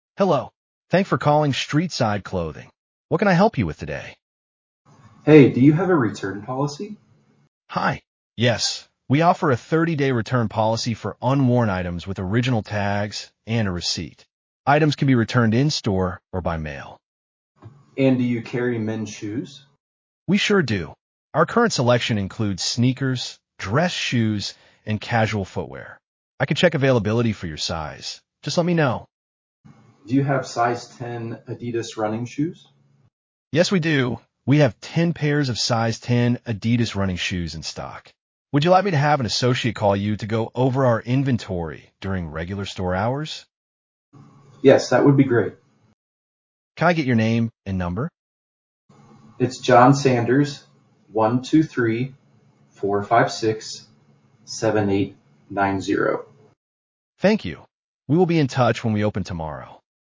Holen Sie sich mit dem KI-Empfangsassistenten einen natürlichen und hilfsbereiten virtuellen Assistenten in Ihr Unternehmen.